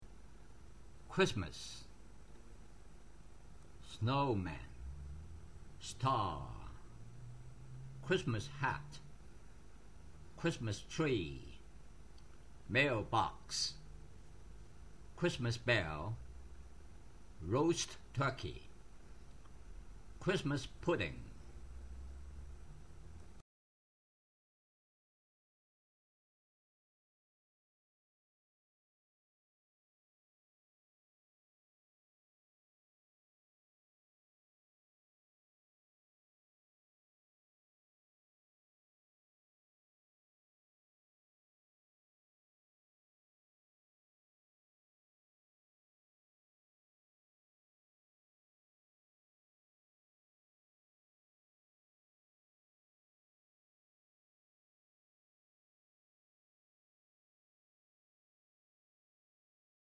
Christmas - 圖片讀音